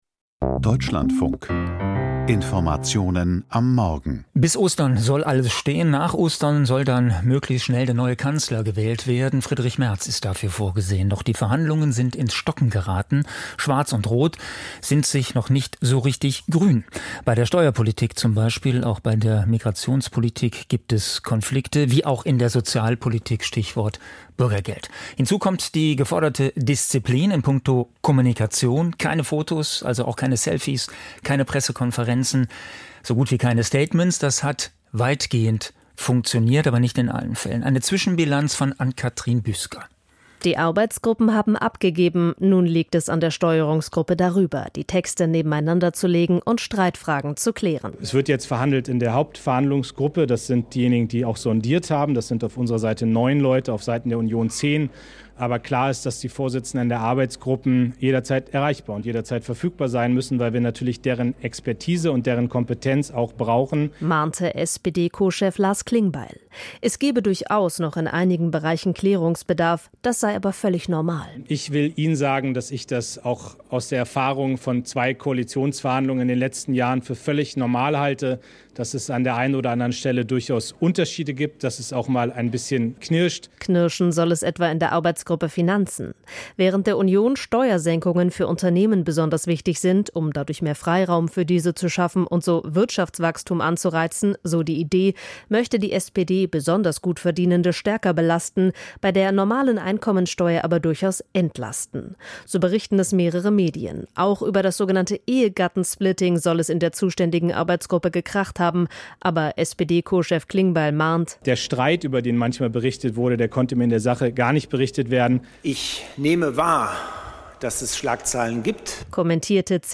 Koalitionsverhandlungen: Stillstand oder Durchbruch? Interview Ralf Stegner, SPD